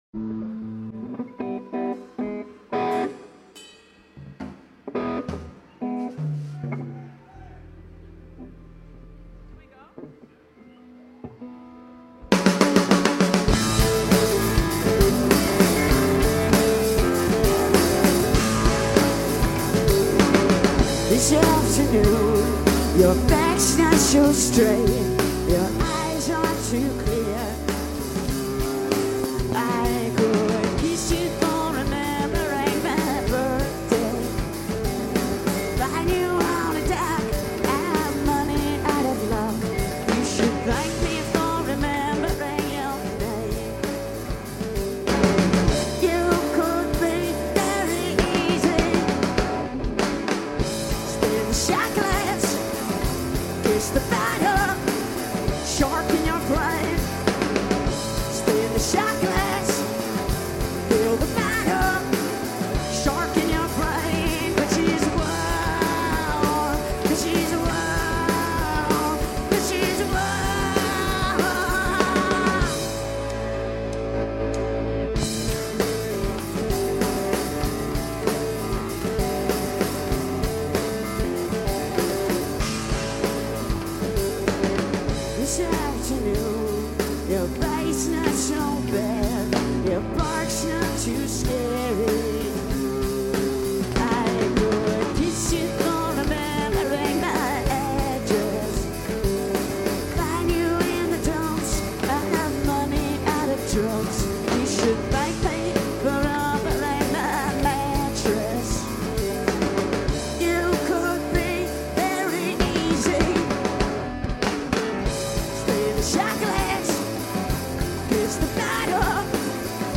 Primavera Sound Festival 2009